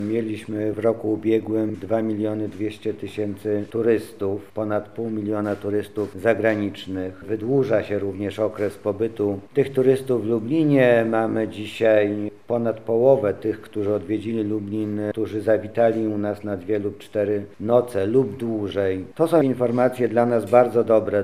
– mówi Mariusz Banach Zastępca Prezydenta Miasta Lublin ds. Oświaty i Wychowania.